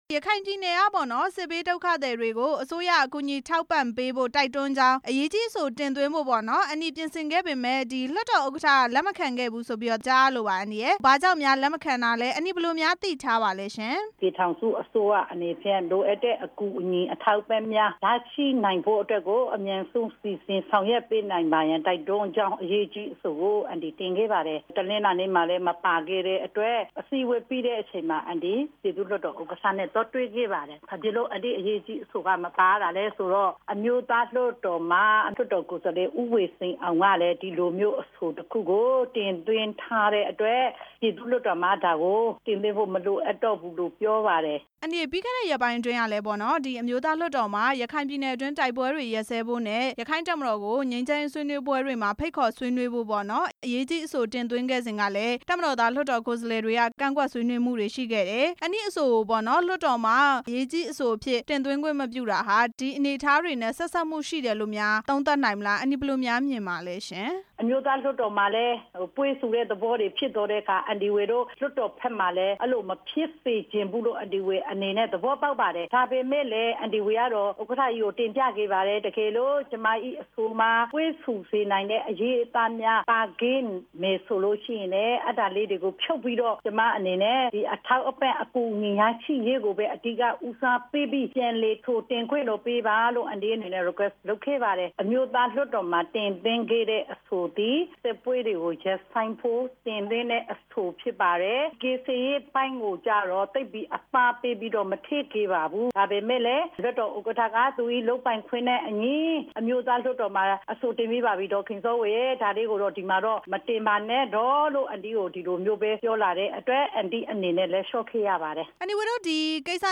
ဒေါ်ခင်စောဝေ ကို မေးမြန်းချက်